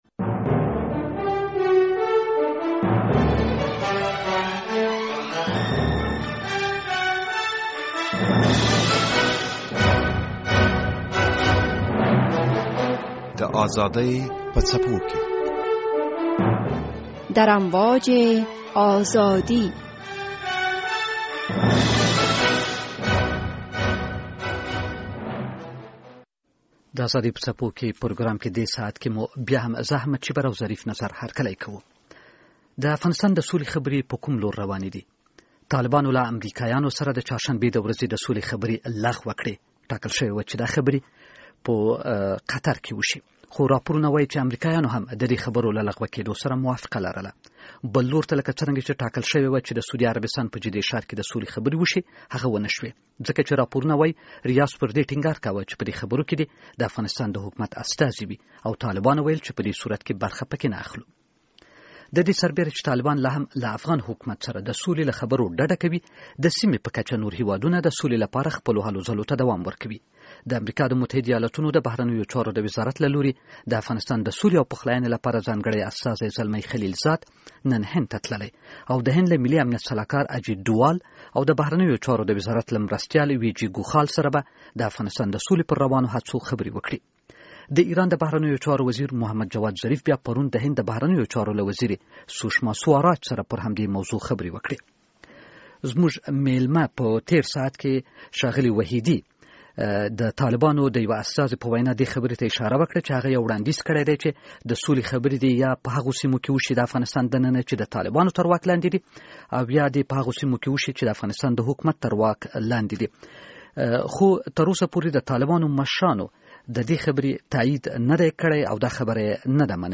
د ازادي په څپو کې دې پروګرام کې په دې اړه بحث لرو.